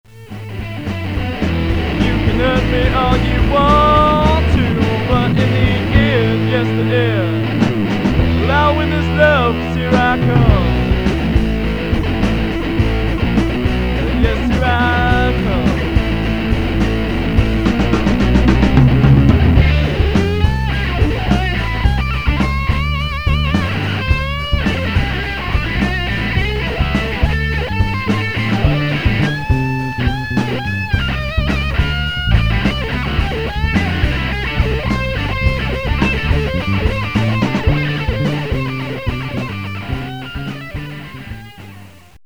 at their live performances